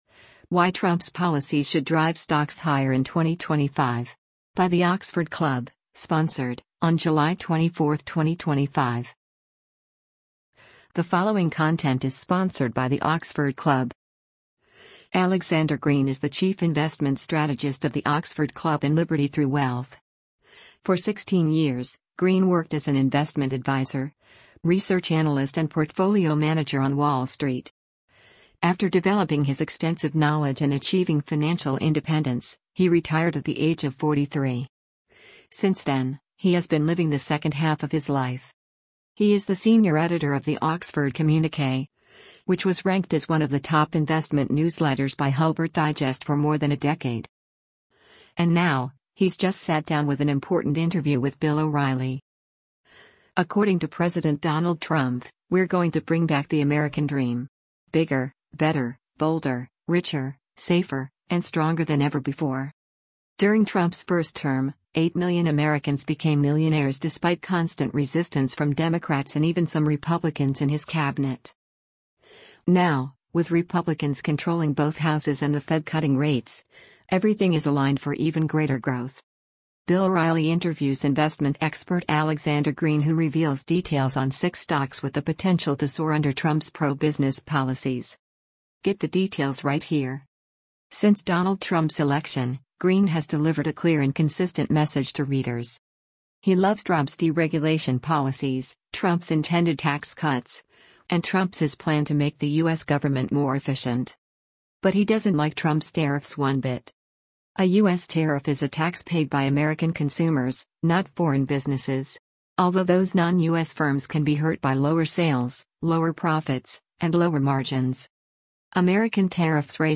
Bill O’Reilly interviews investment expert